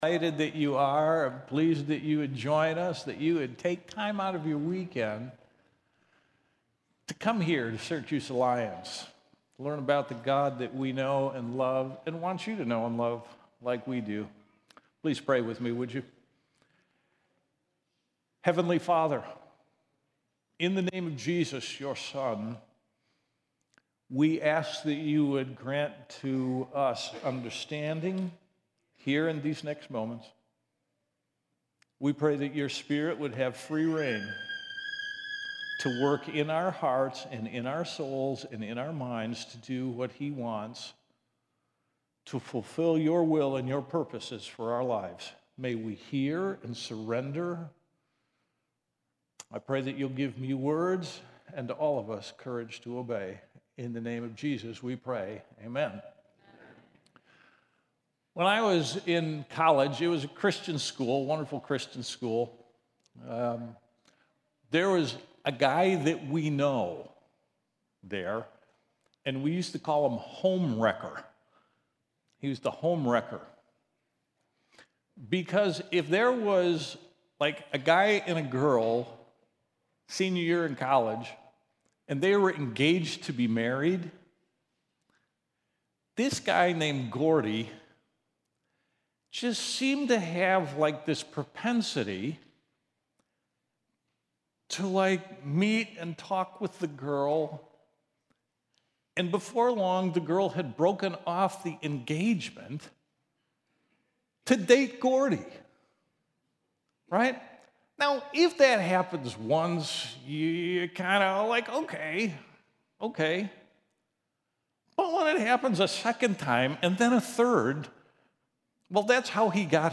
Sermons | Syracuse Alliance Church